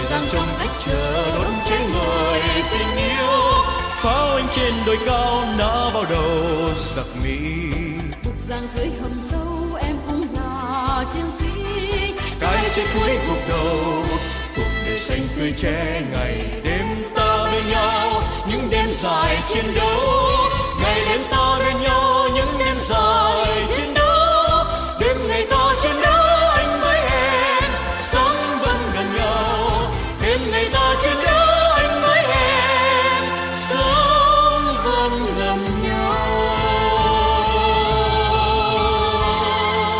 Nhạc Chuông Nhạc Vàng - Nhạc Đỏ